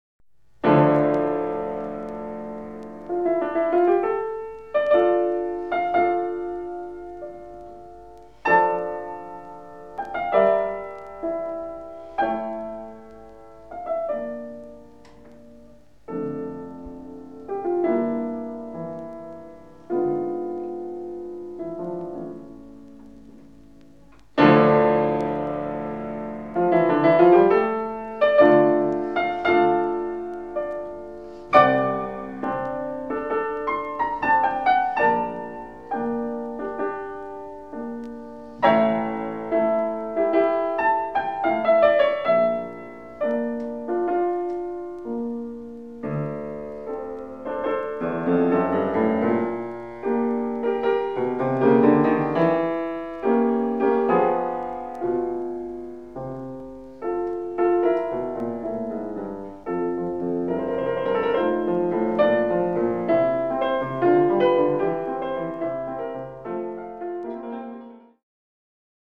• Sonate n°17 en ré majeur, K 576 — 18’14